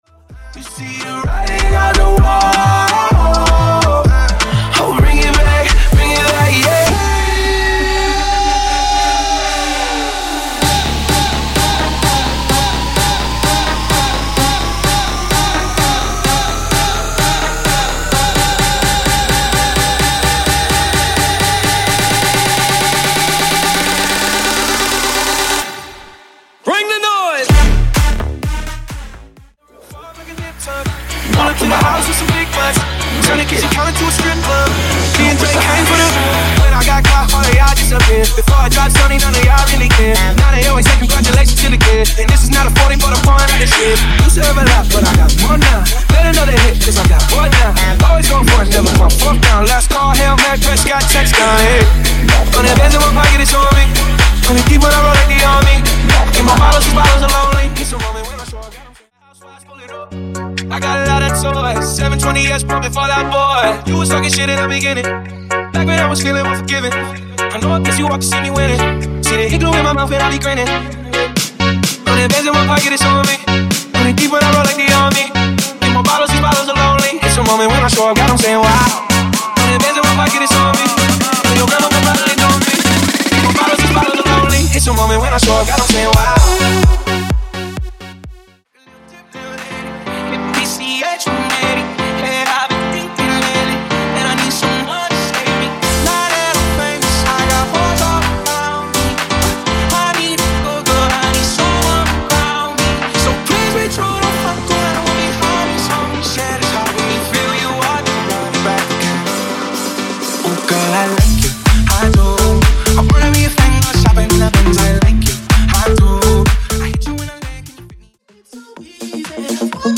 Genre: TOP40 Version: Dirty BPM: 91 Time